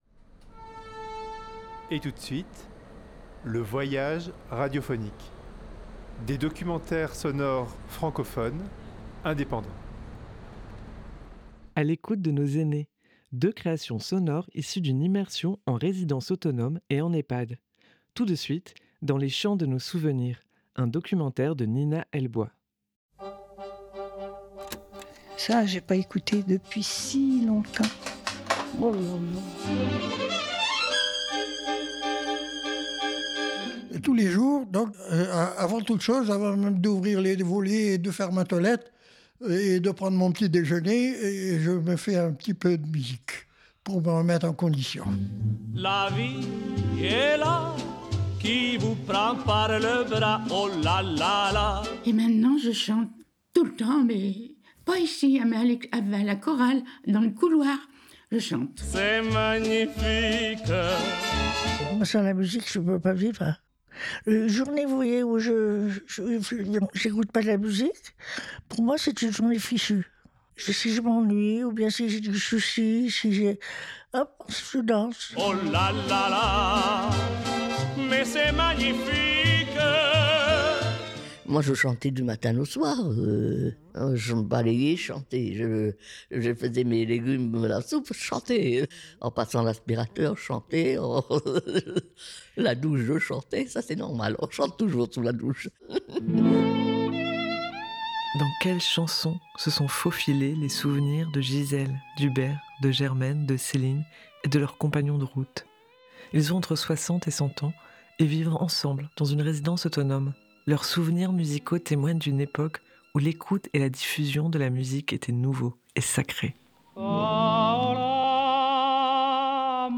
En mélangeant musique et témoignages, ce documentaire nous plonge dans une époque où la musique avait ce quelque chose de sacré : les premiers magnétophones, électrophones, les souvenirs de chants, les premiers bals, les histoires d’amour sur fond de jazz, valse et tango, la place de la radio et de la musique dans les familles.
Nous y avons installé un studio d’écoute et de création sonore. Nous nous sommes baladées dans les lieux avec nos enregistreurs. Nous avons discuté de la vie ici et maintenant à l’EHPAD, son rythme, ses relations sociales et affectives.